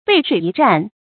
背水一战 bèi shuǐ yī zhàn
背水一战发音
成语正音 背，不能读作“bēi”。